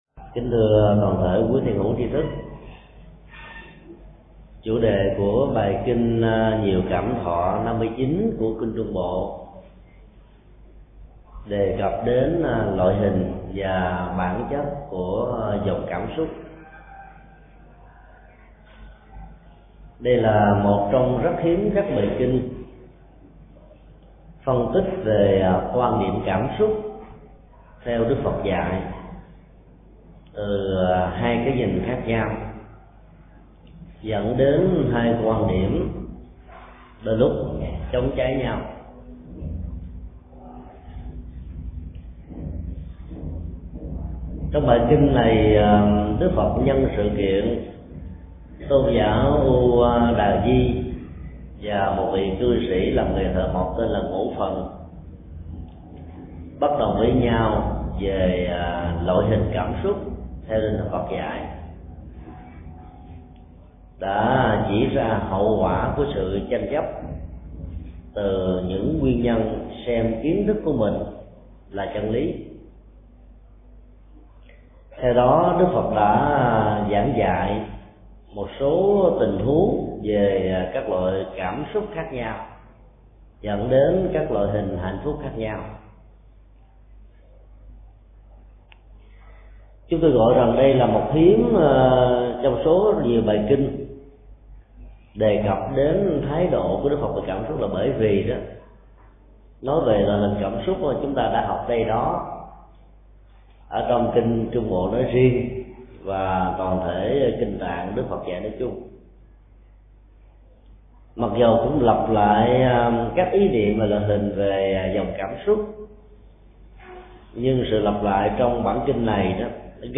Mp3 Pháp Thoại Kinh Trung Bộ 59 (Kinh Nhiều Cảm Xúc) – Loại hình cảm xúc – Thượng Tọa Thích Nhật Từ giảng tại chùa Xá Lợi, ngày 7 tháng 1 năm 2007